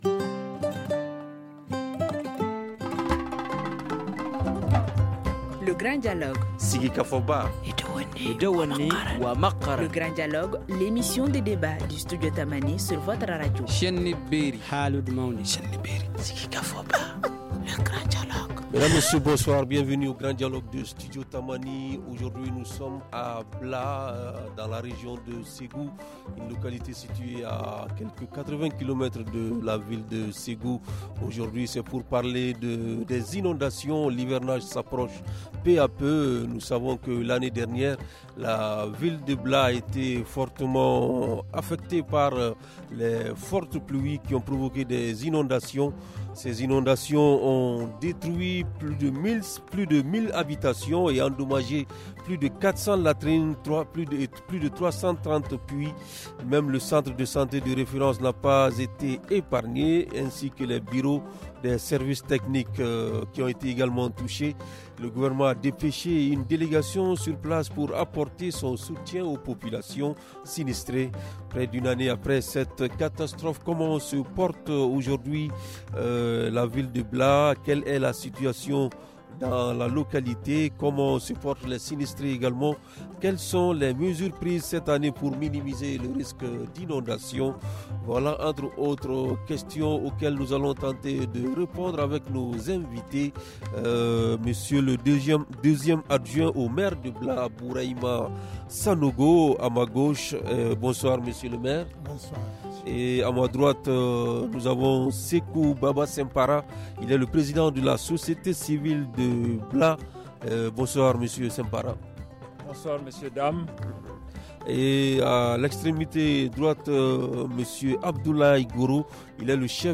Studio Tamani tujours au plus près des populations, pose aujourd’hui, le débat à Bla.